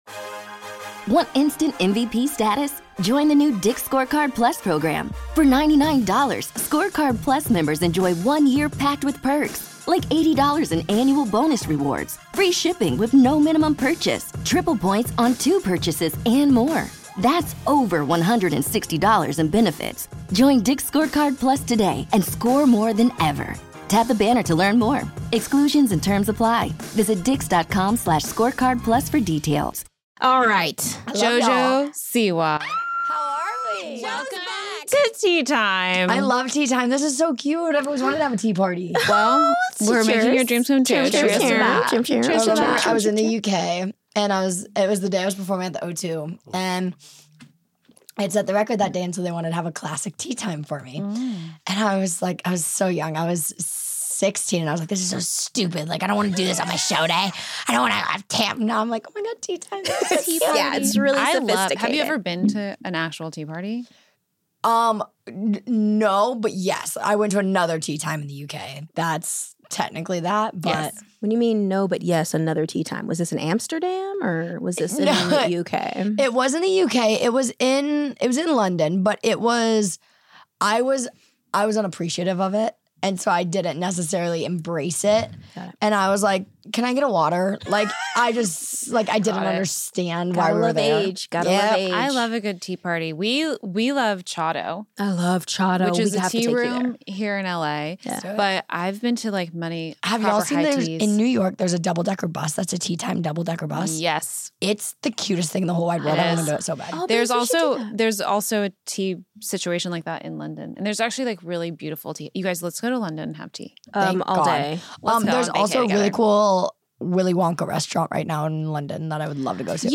There's nothing better than catching up with an old friend! Join us for an energetic chat where we spill all sorts of tea.